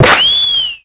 enemy_hitted.wav